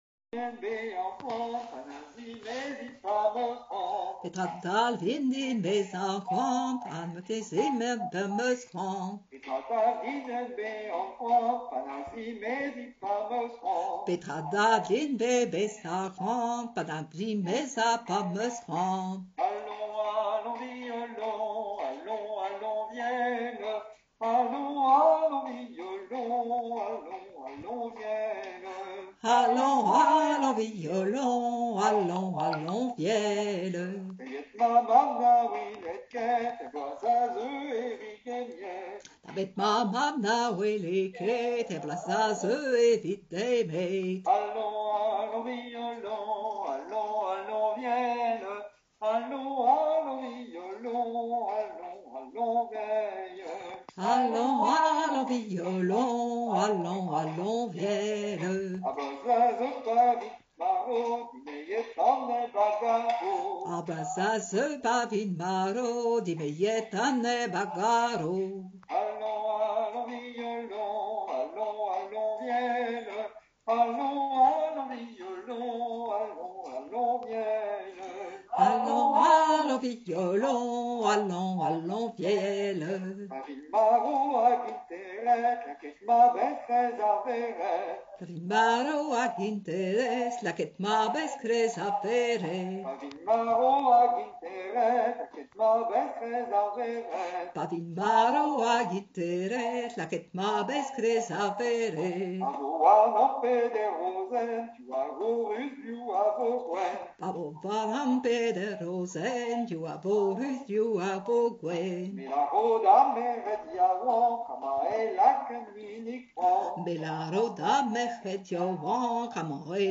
Stage à la Mission bretonne - Ti ar Vretoned
Enregistrement des paroles sans la ritournelle